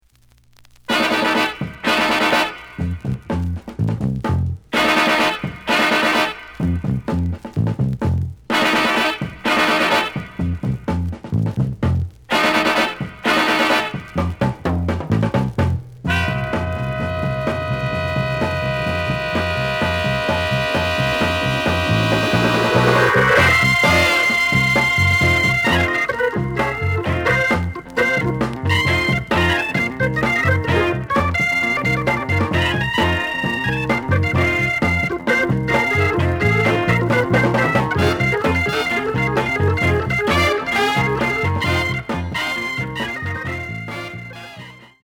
The audio sample is recorded from the actual item.
●Genre: Funk, 60's Funk